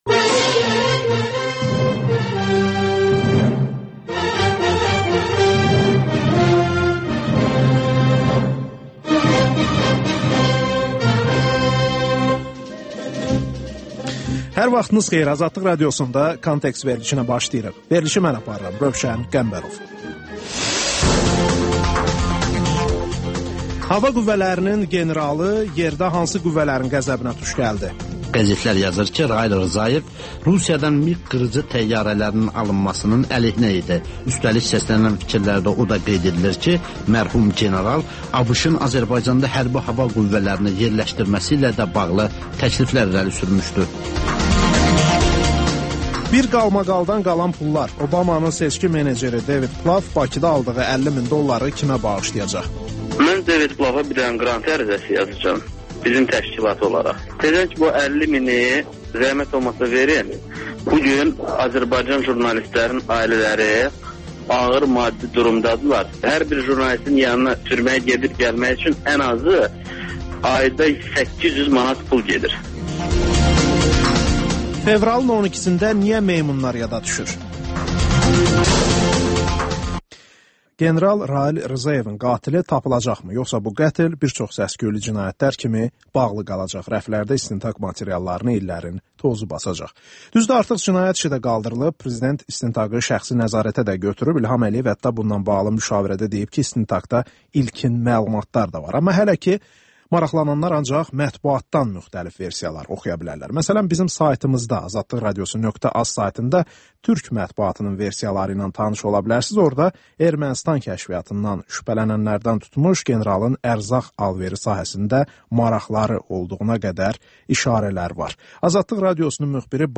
Müsahibələr, hadisələrin müzakirəsi, təhlillər Təkrar